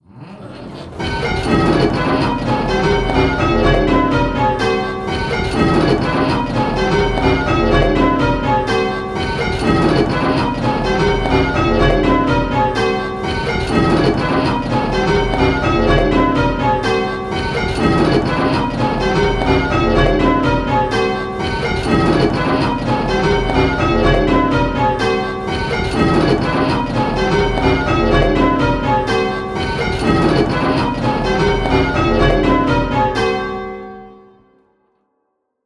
Striking 8 Bell Rounds - Pebworth Bells
Striking 8 Bell Rounds - Round 1